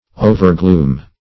Search Result for " overgloom" : The Collaborative International Dictionary of English v.0.48: Overgloom \O`ver*gloom"\, v. t. To spread gloom over; to make gloomy; to overshadow.